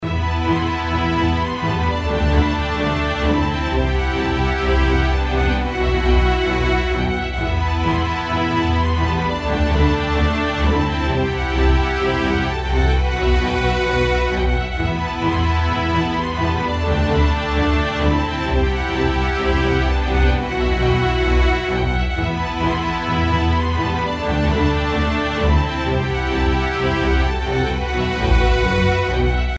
LOOP推奨： LOOP推奨
楽曲の曲調： SOFT
怪しい雲行きでも立ち向かうシーンのBGM等に